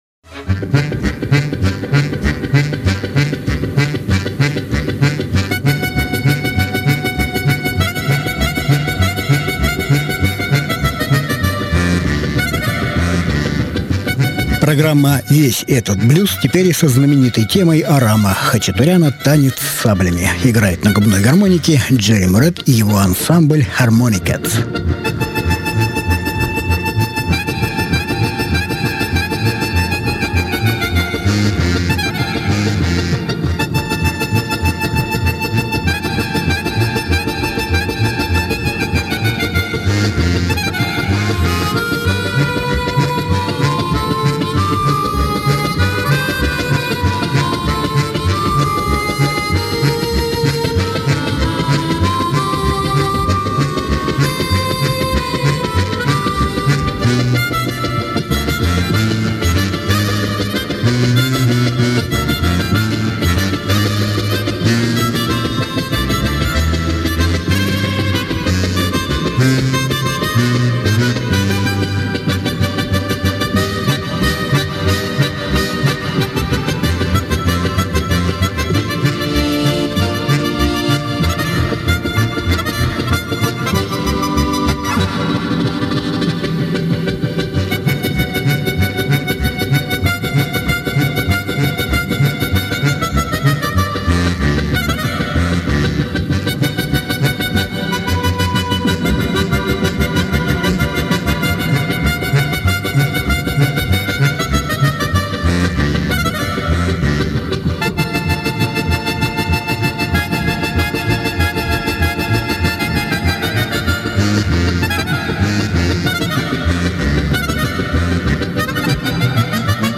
Различные альбомы Жанр: Блюзы СОДЕРЖАНИЕ 10.12.2018 1.